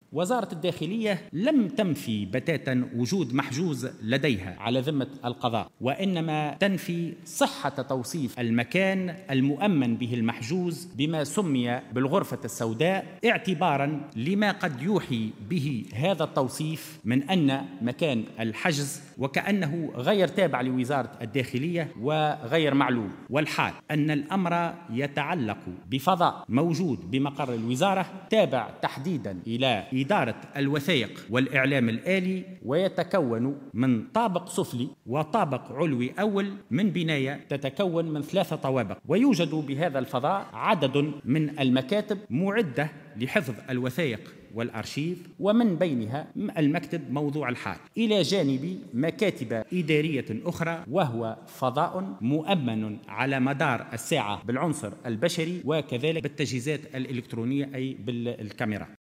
قال وزير الداخلية هشام الفوراتي، خلال جلسة مساءلة له بمجلس نواب الشعب حول 'الغرفة السوداء' اليوم الاثنين، إن وزارته لم تنفي وجود محجوز لها على ذمة القضاء في قضية الشهيدين شكري بلعيد ومحمد البراهمي.